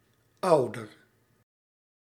Ääntäminen
France: IPA: /pa.ʁɑ̃/